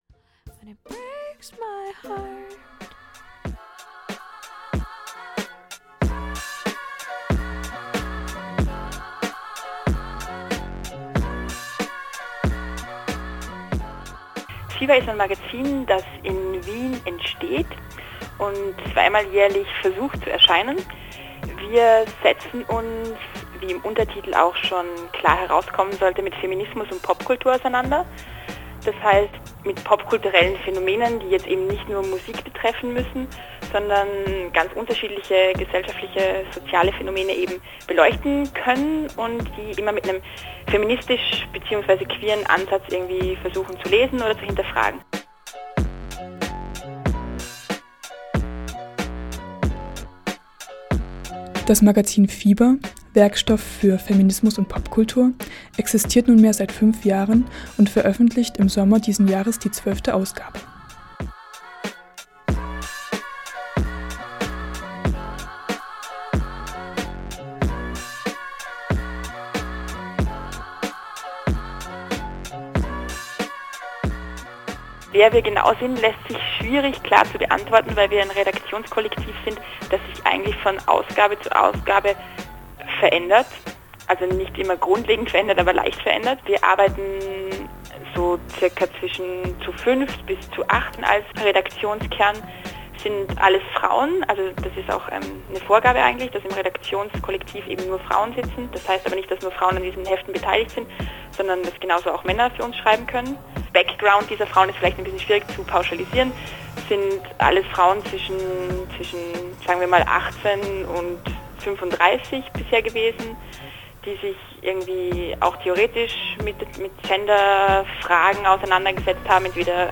Fiber-Interview, Radio Latifa 07/2007
In der Juli-Sendung von 2007 interviewte Radio-Latifa die Macherinnen der Wiener Zeitschrift Fiber.